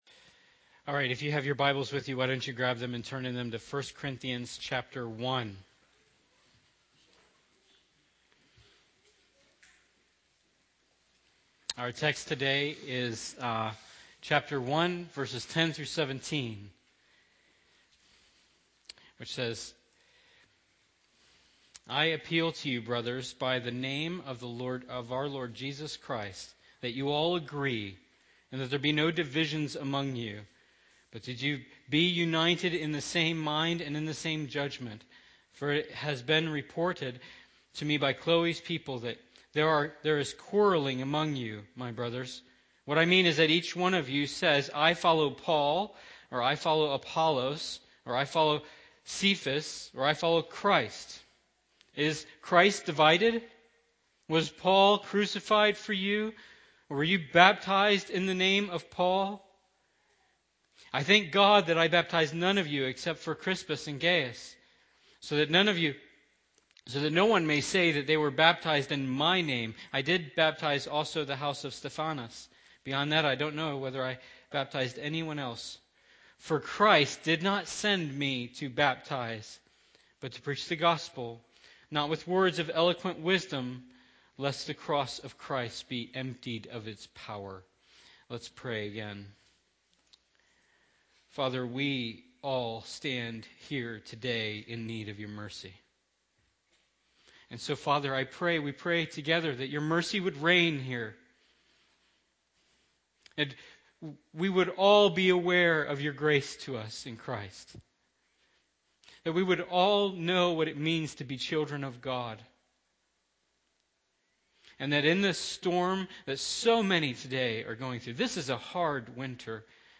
Sermons | Ridgeview Bible Church